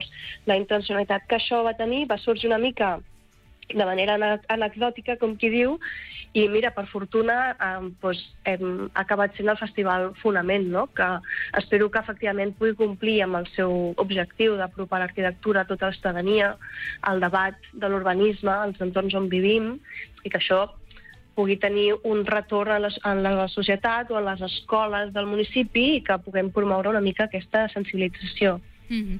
En una entrevista concedida al programa Supermatí, la regidora d’Urbanisme, Anna Carlero, ha explicat que la idea del festival neix de la voluntat de fer accessible el patrimoni i el debat urbanístic.